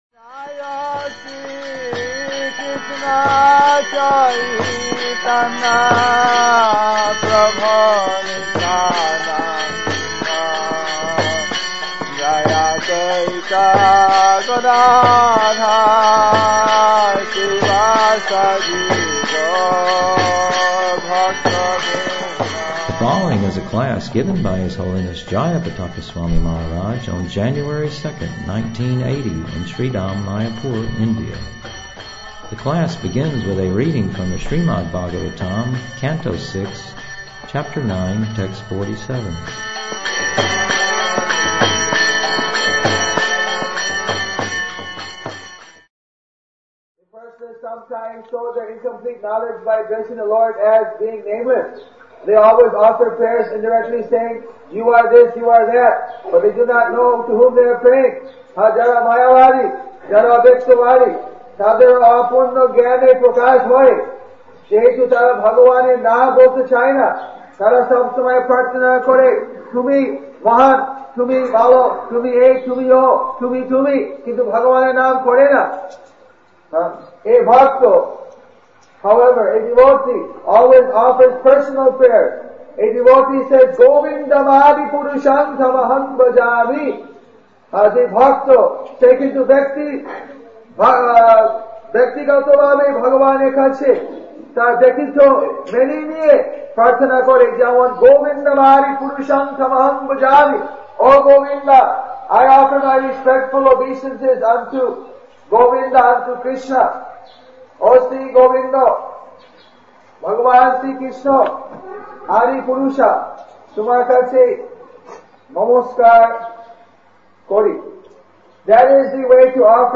SUNDAY FEAST LECTURE SB(5.12.8)